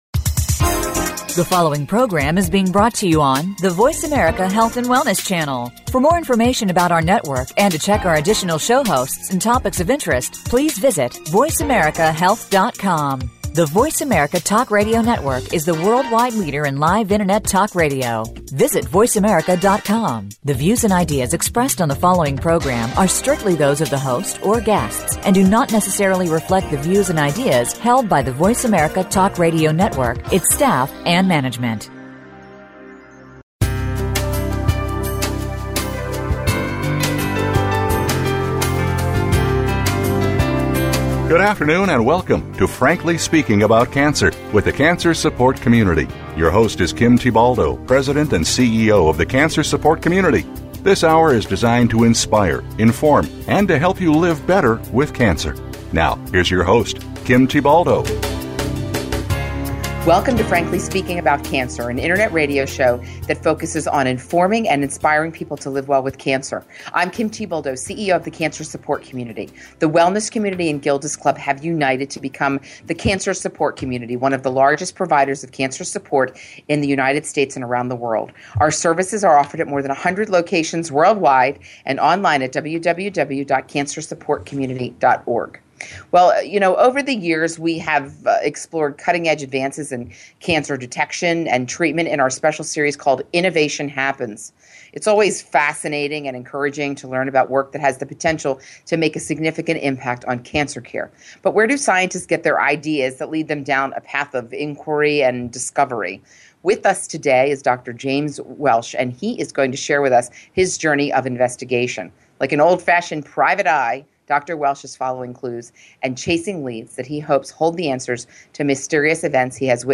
Encore: A Search for Answers in Unlikely Places- A conversation